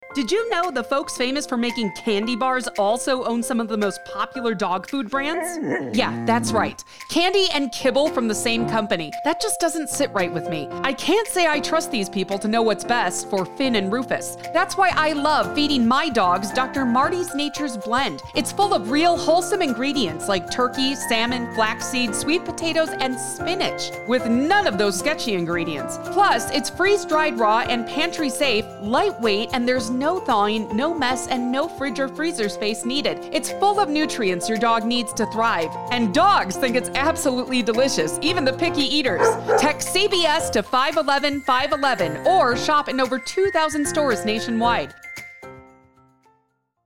Commercial Demo
Voice Age
Middle Aged